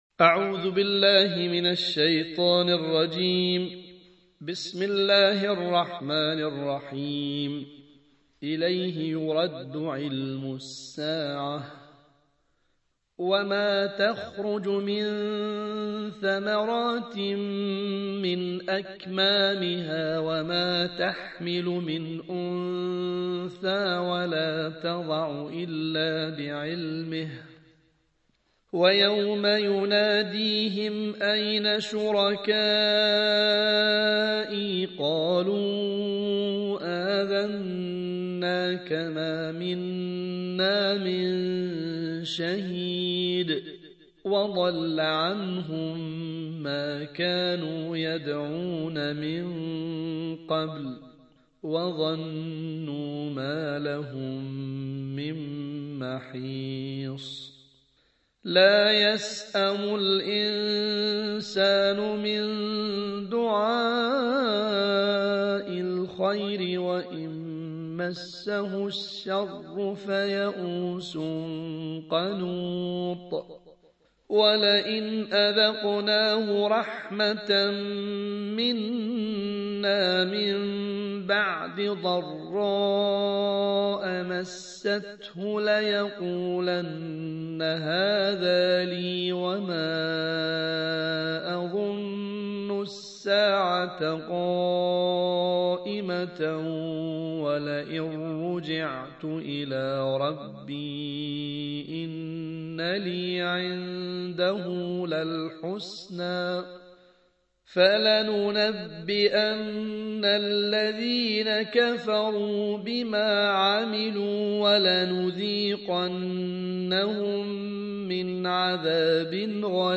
الجزء الخامس والعشرون / القارئ